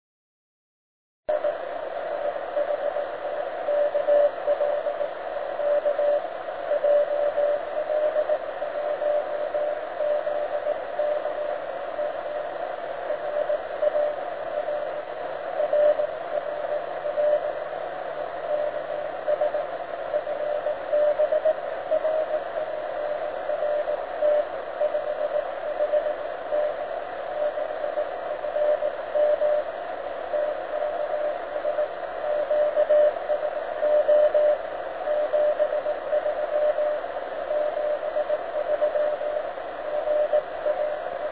PM95UM OOO OOO CW 9548